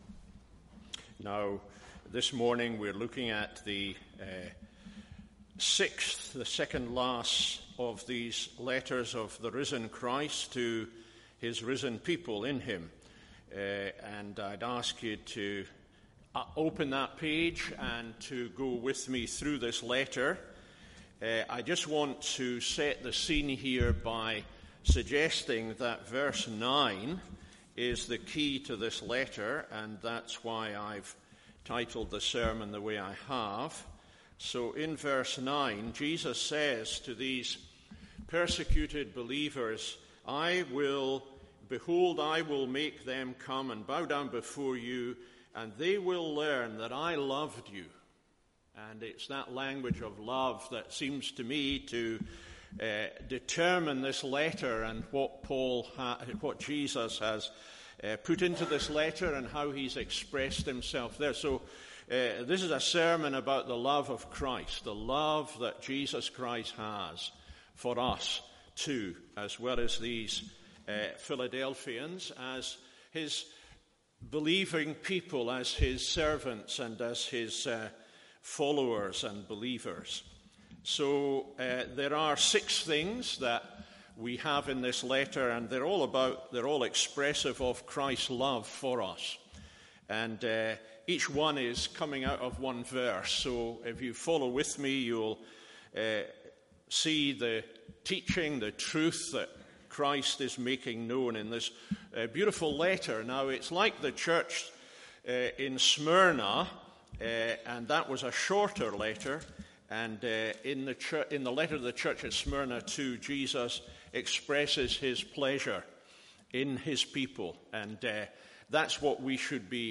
MORNING SERVICE Revelations 3:7-13…